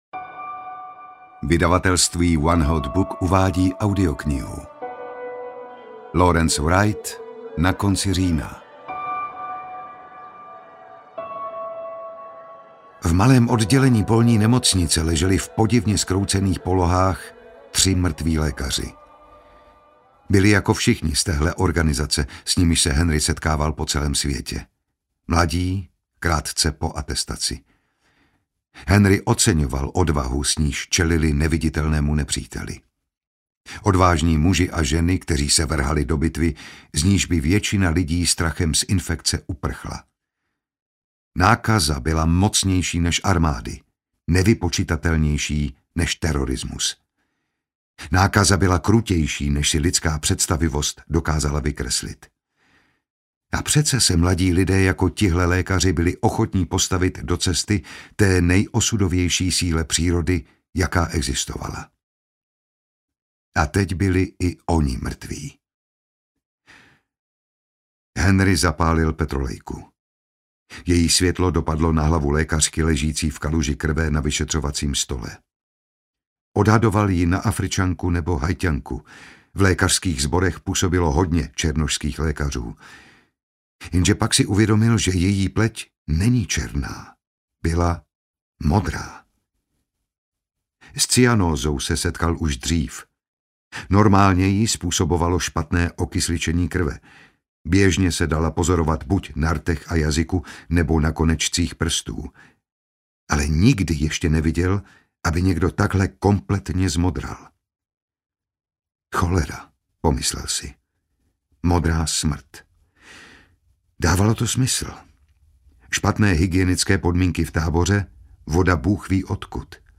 Na konci října audiokniha
Ukázka z knihy